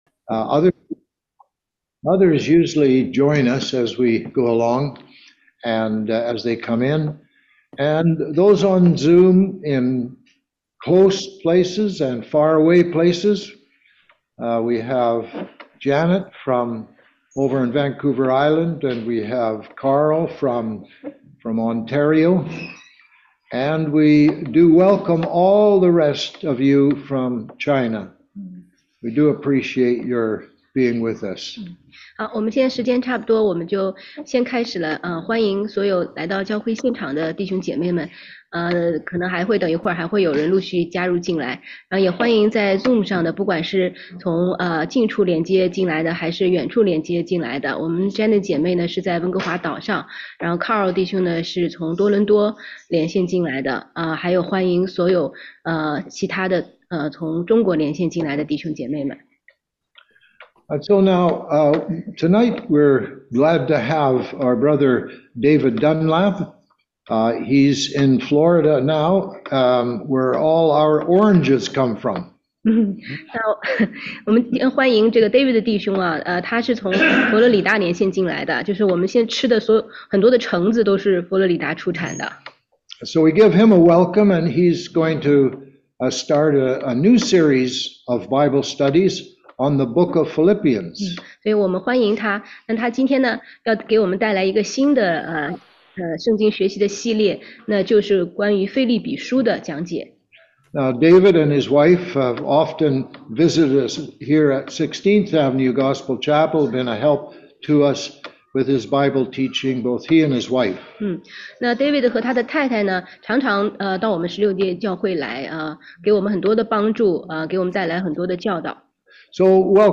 16街讲道录音
中英文查经